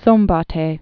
(sōmbôt-hā)